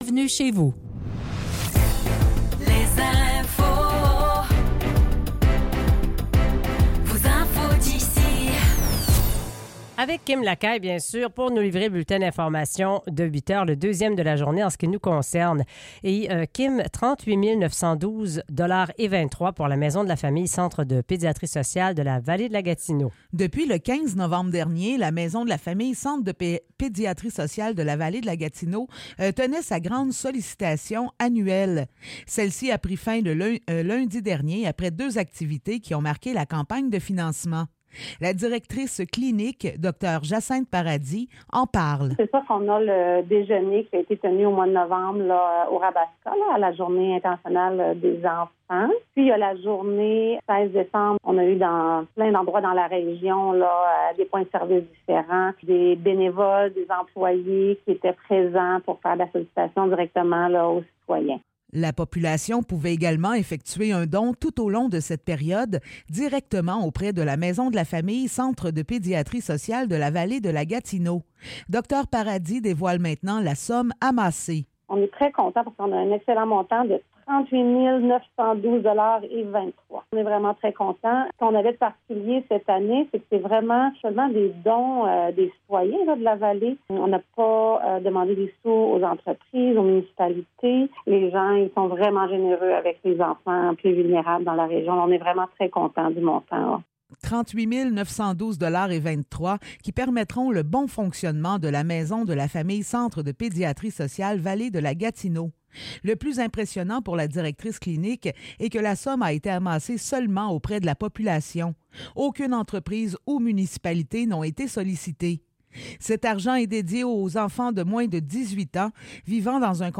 Nouvelles locales - 18 janvier 2024 - 8 h